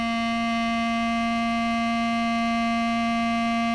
These conditions result in the presence of only odd harmonics in the sound.
The absence is even harmonics is (part of) what is responsible for the "warm" or "dark" sound of a clarinet compared to the "bright" sound of a saxophone.
Here is a WAV file with a recorded clarinet sound:
Clarinet.
clarinet.wav